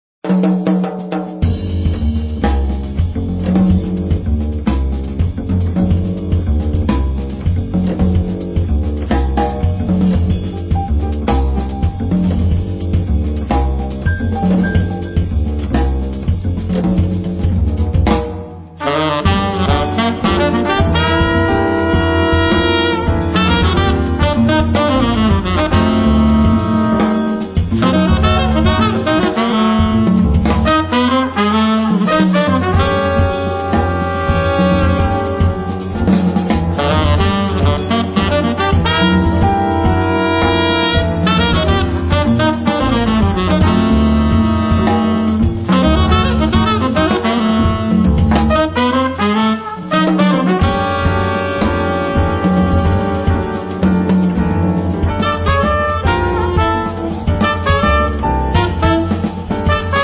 Sassofono tenore e soprano
tromba e flicorno
Pianoforte
Contrabbasso
Batteria
quello afro-cubano